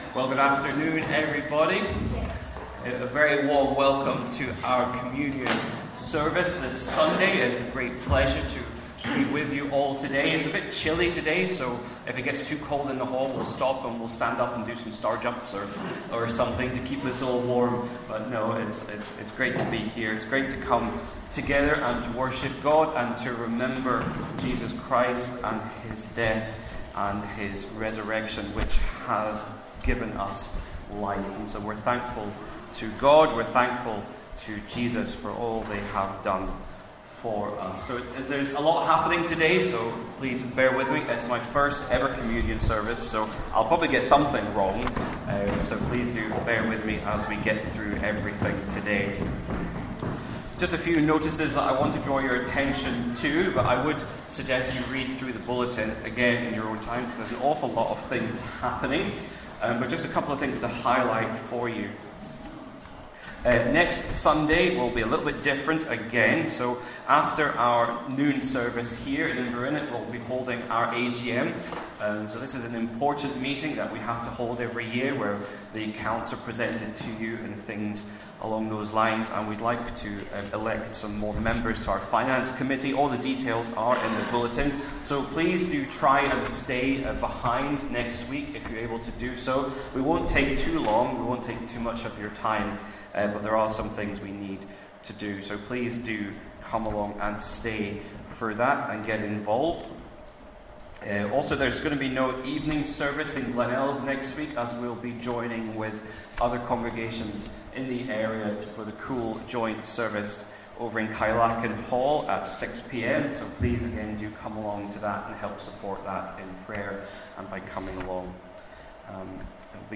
12 Noon Communion Service
12-Noon-Communion-Service-.mp3